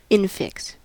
Ääntäminen
Ääntäminen US US US : IPA : /ˈɪnfɪks/ Haettu sana löytyi näillä lähdekielillä: englanti Käännöksiä ei löytynyt valitulle kohdekielelle.